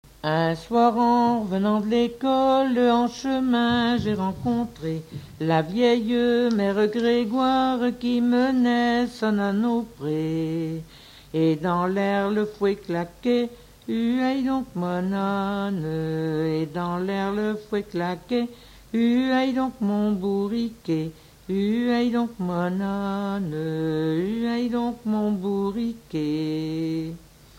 La jeunesse d'une vieille femme qui faisait semblant d'étudier Fonction d'après l'analyste gestuel : à marcher
Genre laisse
Catégorie Pièce musicale inédite